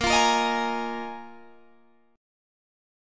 A#7sus4 Chord
Listen to A#7sus4 strummed